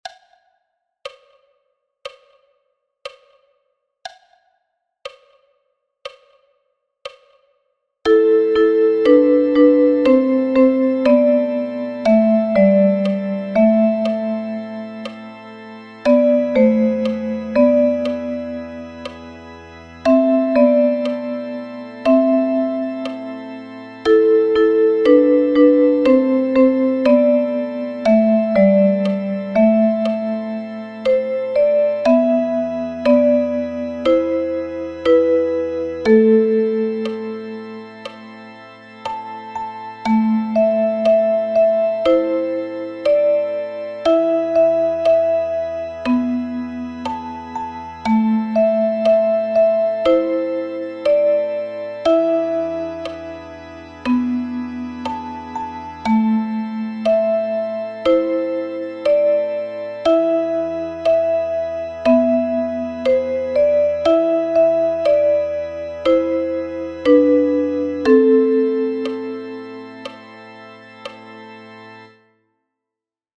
notiert als Duette für Sopranblockflöte und Altblockflöte.